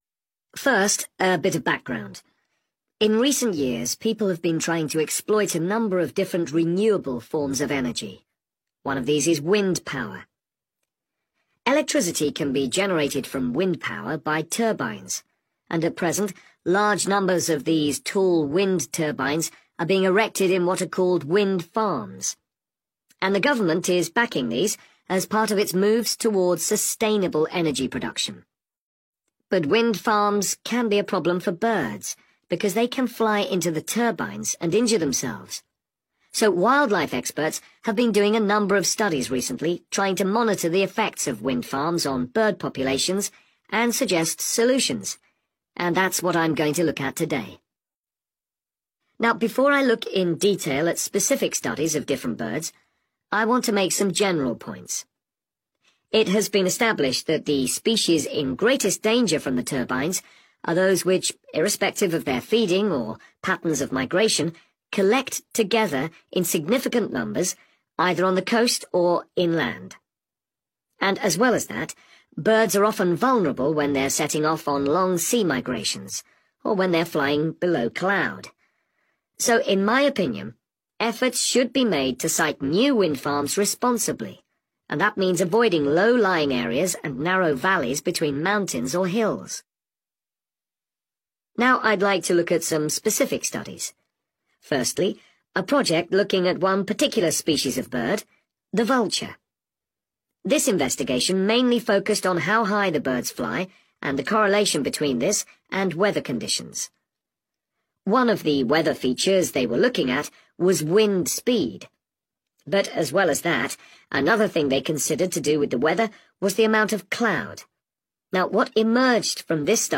31. The speaker says he is going to report on